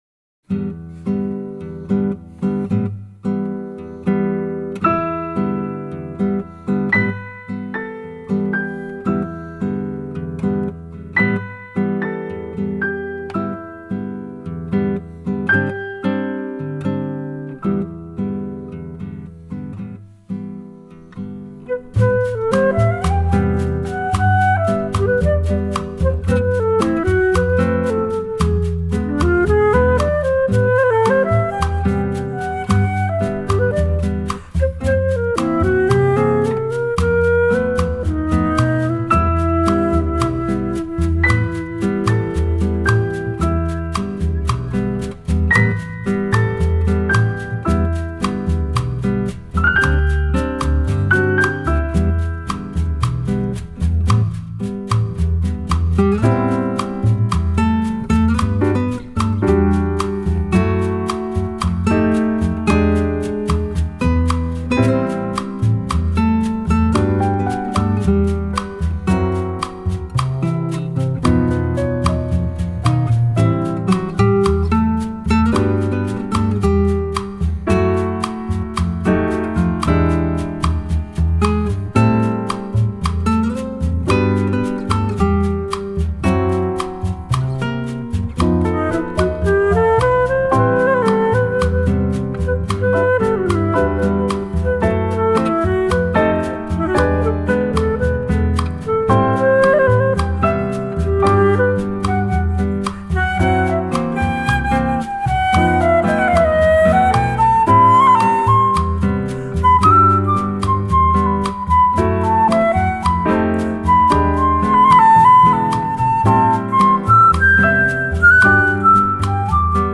★ 滿載拉丁熱情、爵士、探戈與古典齊聚一堂的音樂小小冒險！
音質清澈通透、密度超高！
長笛、拉丁樂器的巧妙運用，音樂更顯色彩繽紛、清新雋永
flute,vibrandoneon
saxophone
guitar,mandolin
piano,accordion
double bass
percussion,vibraphone,bells
violoncello